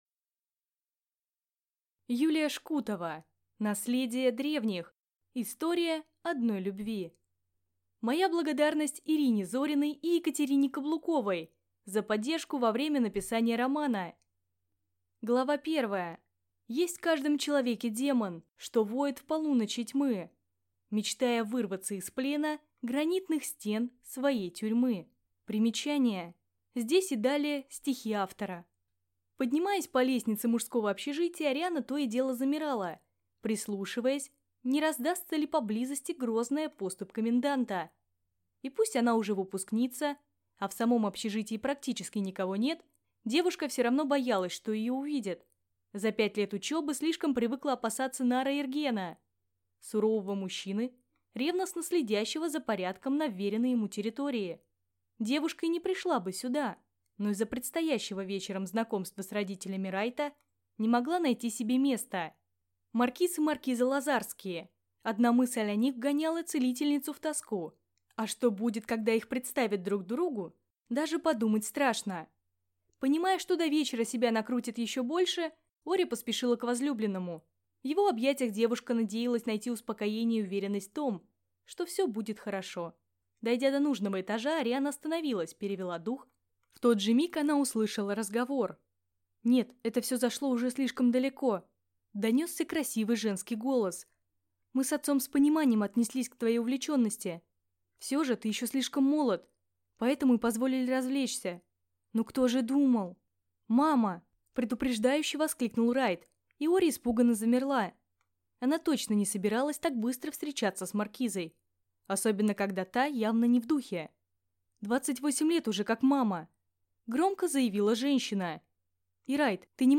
Аудиокнига Наследие древних.